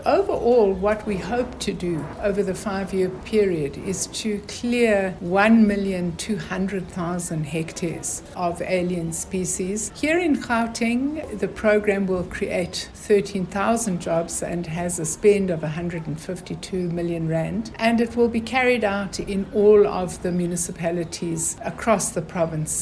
Creecy says the programme will clear 74-thousand-781 hectares over a five-year period from 2023/2024, creating 13-thousand-615 job opportunities: